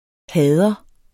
Udtale [ ˈhæːðʌ ]